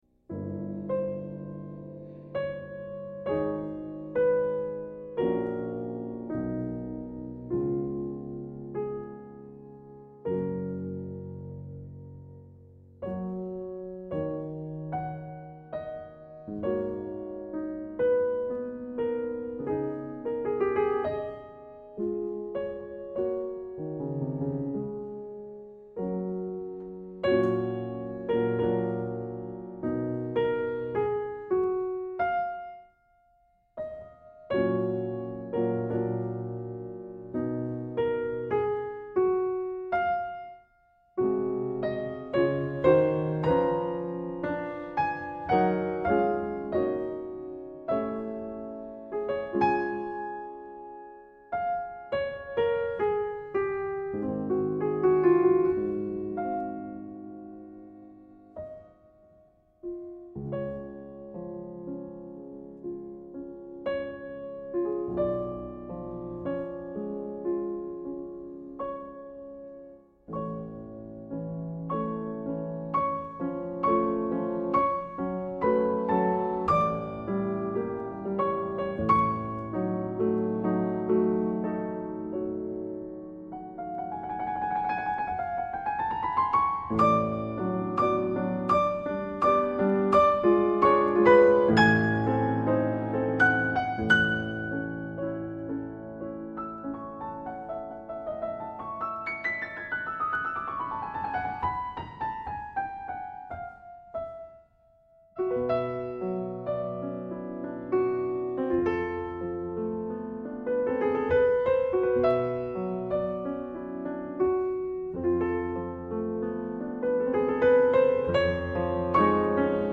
concert pianist